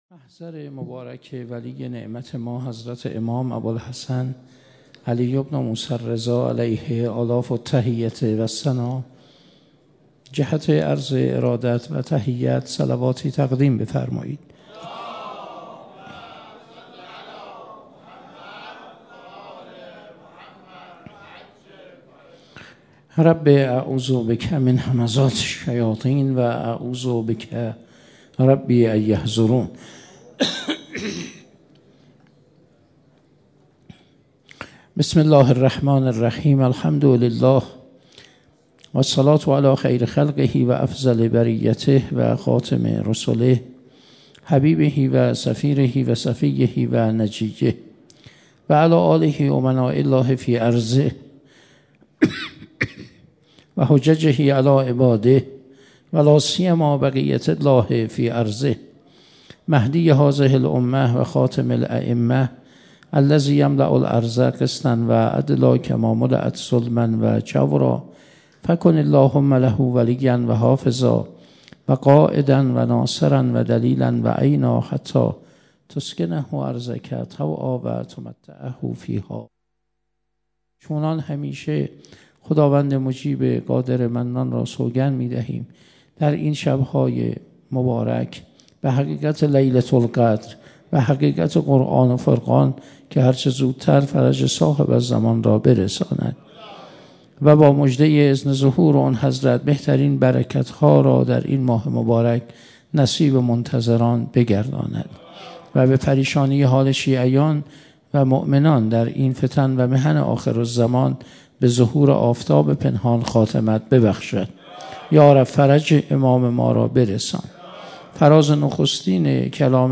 21 اردیبهشت 98 - حسینیه انصار الحسین - سخنرانی